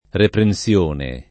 reprensione [ repren SL1 ne ]